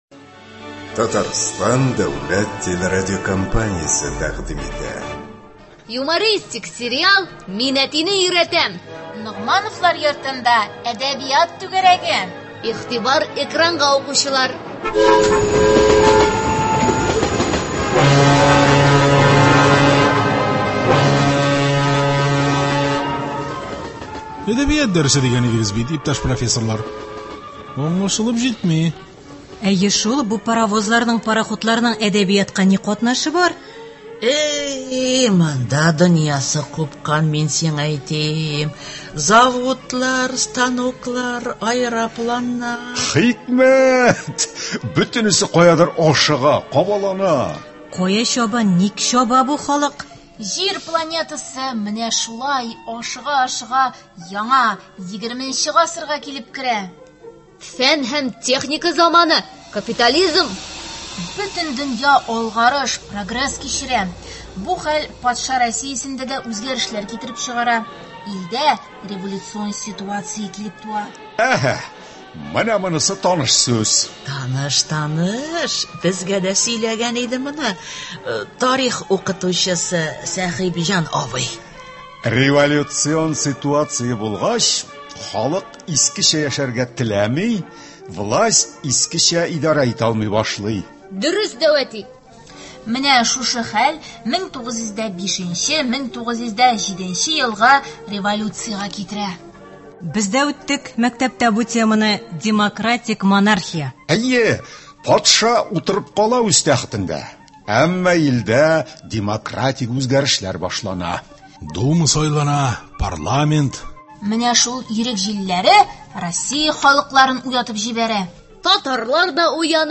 Радиосериал.